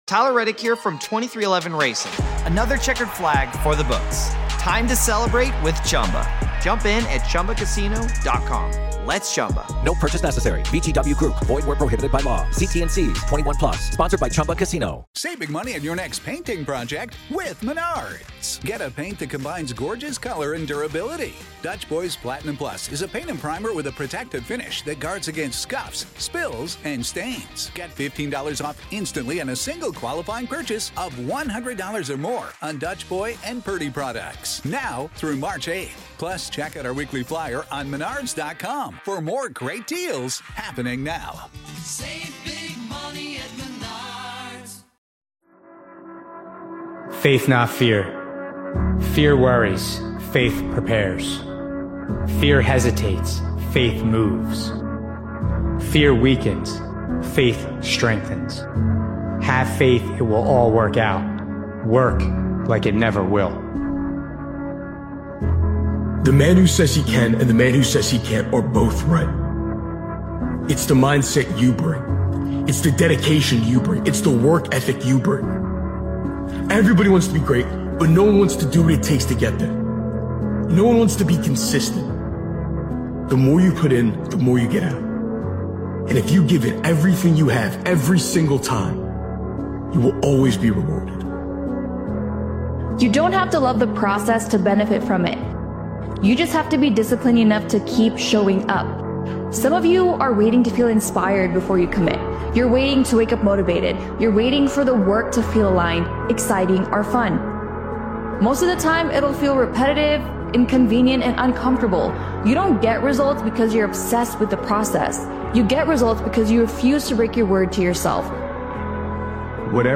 Powerful Motivational Speech is a powerful and humbling motivational speech created and edited by Daily Motivations. This impactful motivational speeches compilation is about commitment without applause—showing up when no one is watching, grinding when no one notices, and staying focused without external validation.